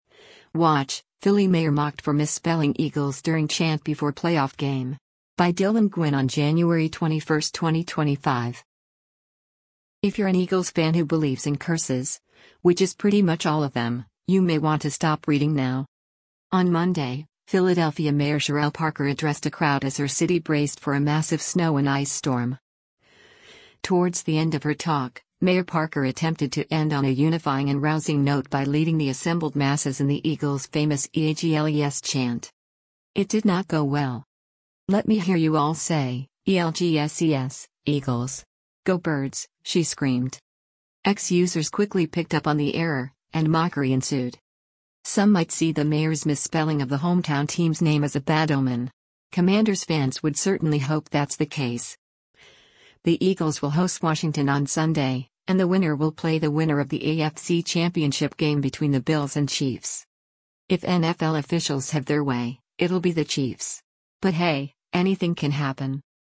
On Monday, Philadelphia Mayor Cherelle Parker addressed a crowd as her city braced for a massive snow and ice storm. Towards the end of her talk, Mayor Parker attempted to end on a unifying and rousing note by leading the assembled masses in the Eagles’ famous “E-A-G-L-E-S” chant.
“Let me hear you all say, E-L-G-S-E-S, Eagles! Go birds,” she screamed.